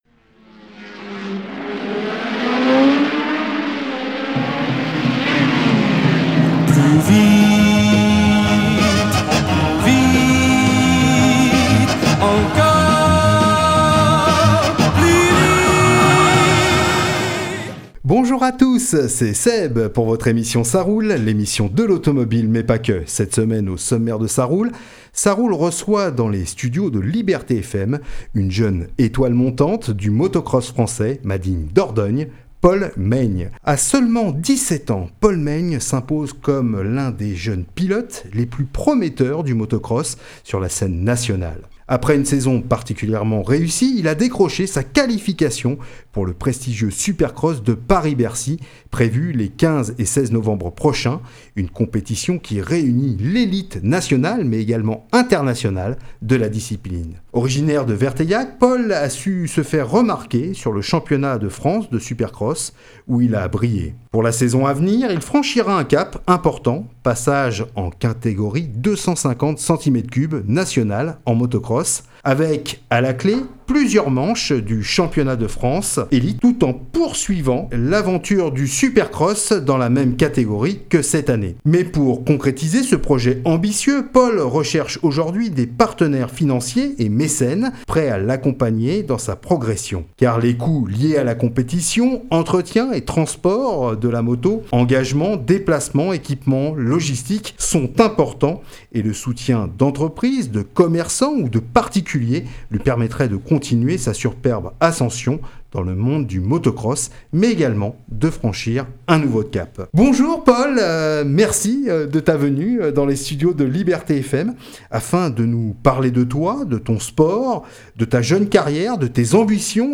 "ÇA ROULE" accueille dans les studios de Liberté FM